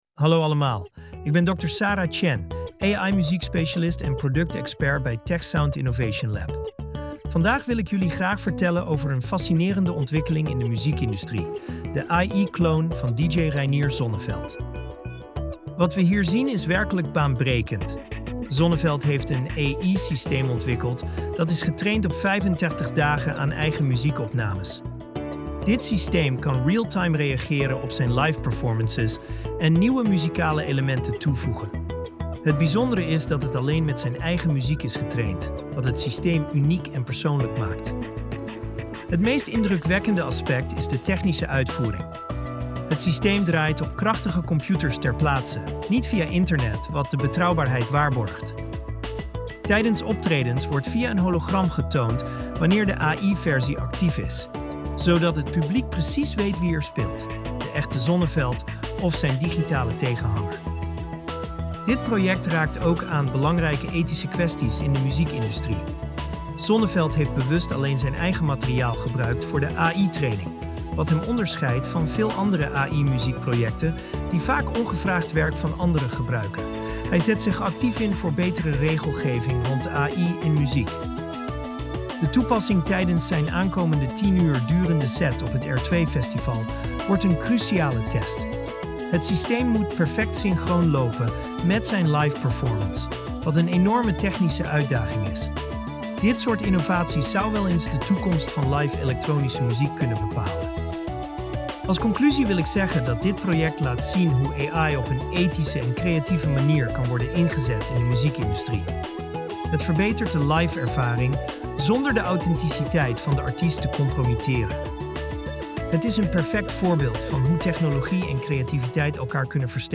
Podcast gegenereerd van tekst content (4467 karakters)